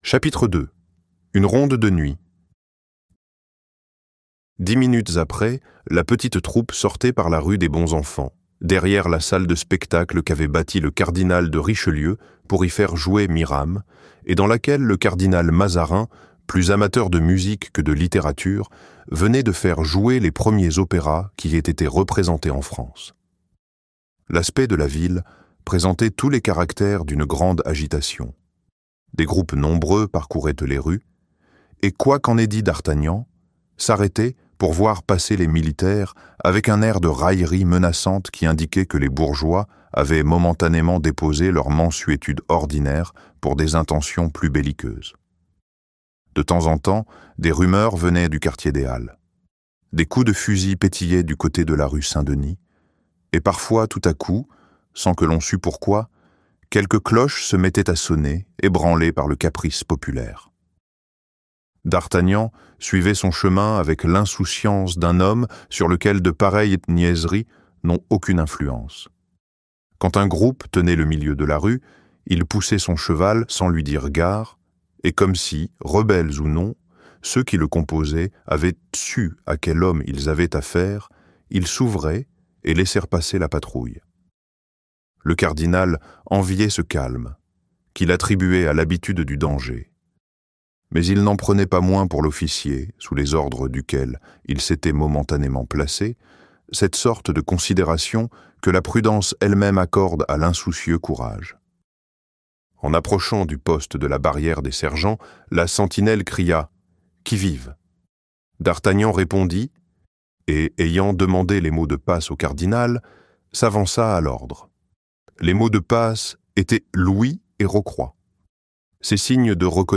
Vingt ans après - Livre Audio